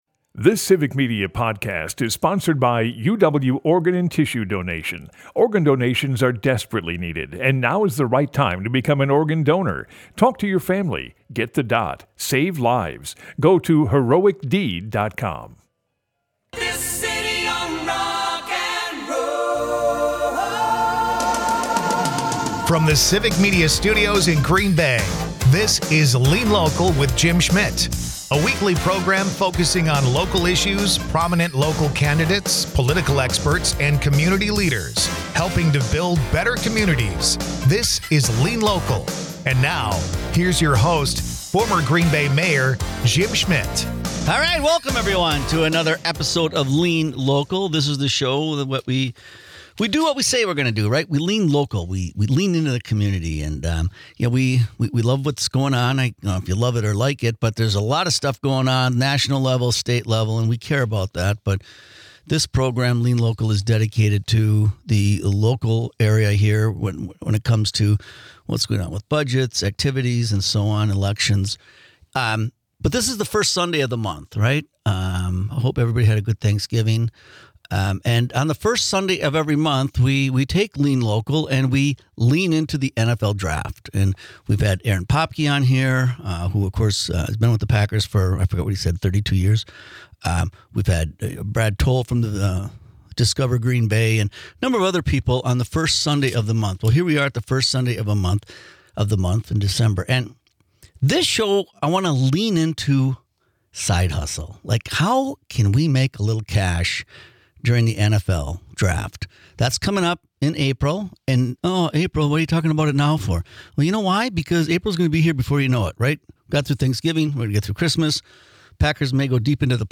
Dive into the heart of community issues with 'Lean Local,' hosted by former Green Bay Mayor Jim Schmitt.
Then Jim has a discussion on Uber driving and Doordash. Lean Local is a part of the Civic Media radio network and airs Sunday's from 1-2 PM on WGBW .